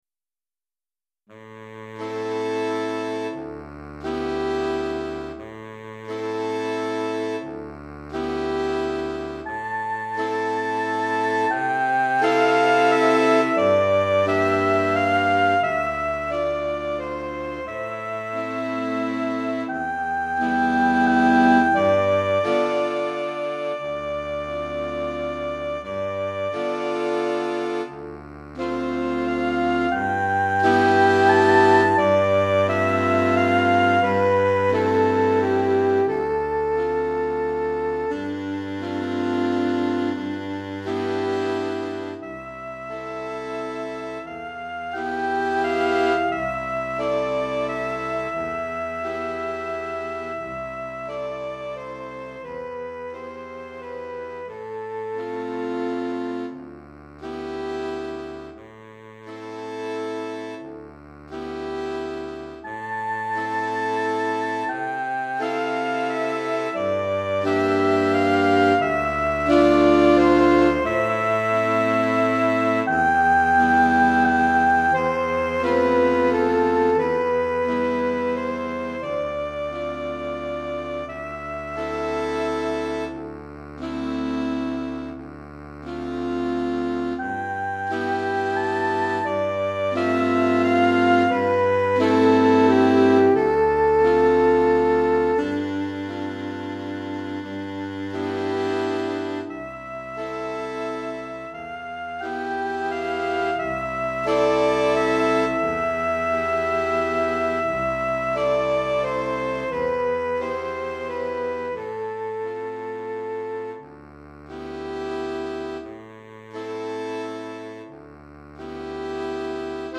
5 Saxophones